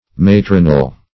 Search Result for " matronal" : The Collaborative International Dictionary of English v.0.48: Matronal \Mat"ron*al\, a. [L. matronalis.]
matronal.mp3